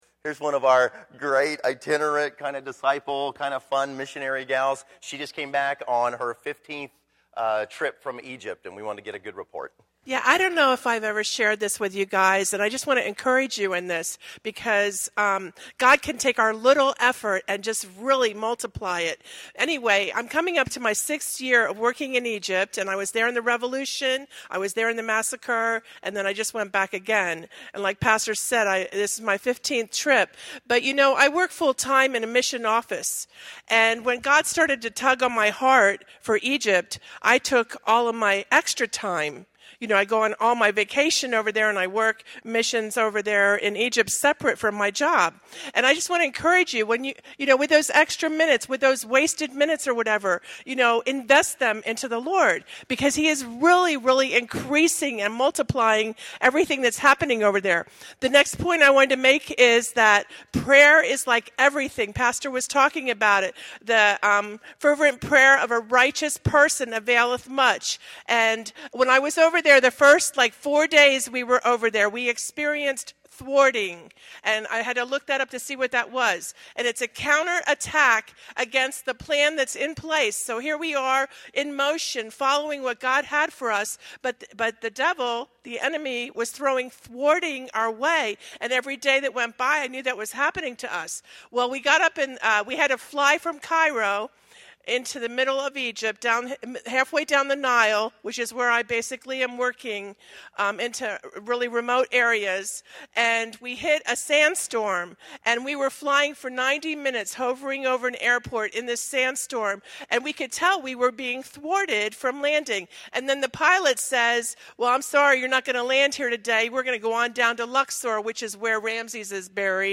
Category: Testimonies